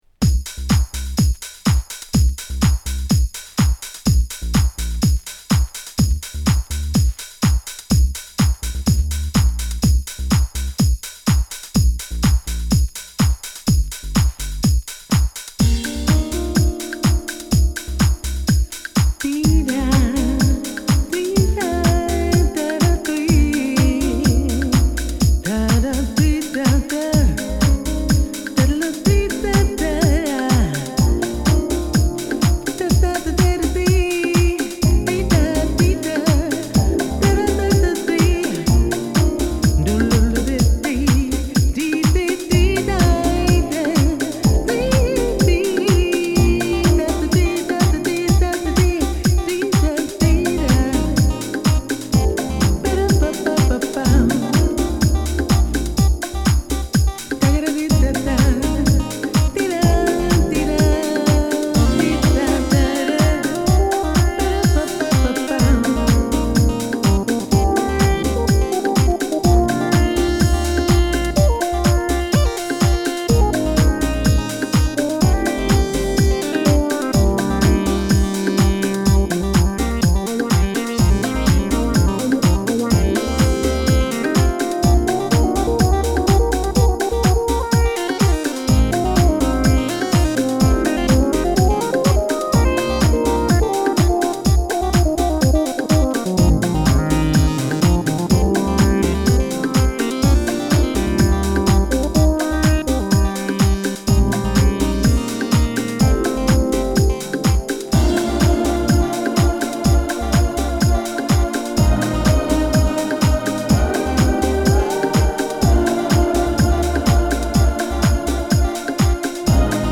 ジャジーなピアノやサックス
ディープハウス好作！
＊試聴はA1→A2→AAです。